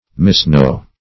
Misknow \Mis*know"\, v. t.